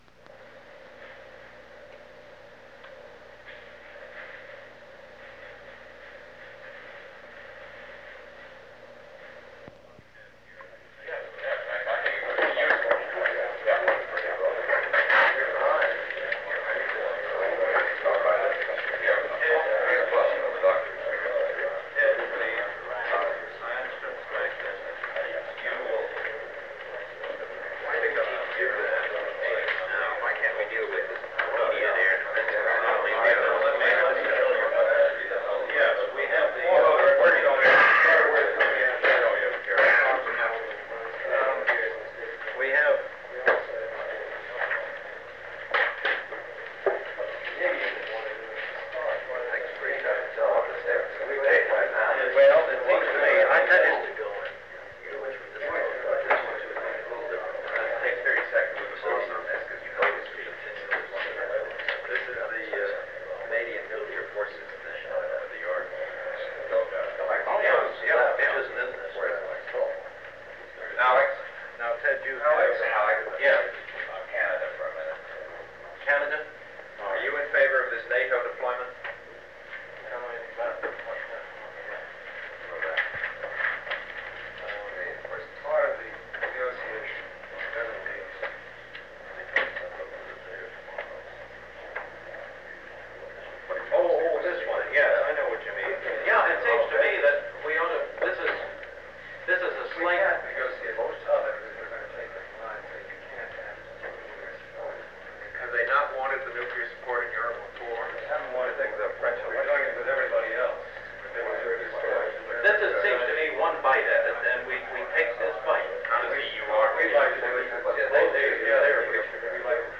Secret White House Tapes | John F. Kennedy Presidency Informal Advisers’ Meeting on Cuba Rewind 10 seconds Play/Pause Fast-forward 10 seconds 0:00 Download audio Previous Meetings: Tape 121/A57.